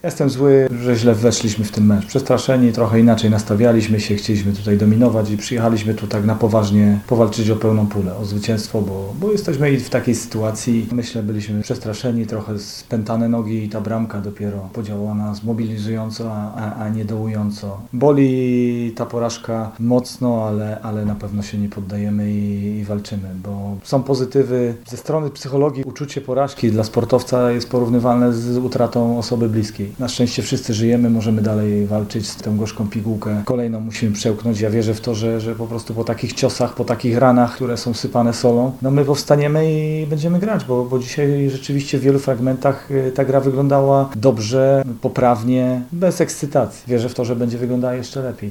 – Uczucie porażki dla sportowca jest porównywalne ze stratą osoby bliskiej – powiedział z kolei na konferencji pomeczowej Piotr Stokowiec, trener ŁKS-u.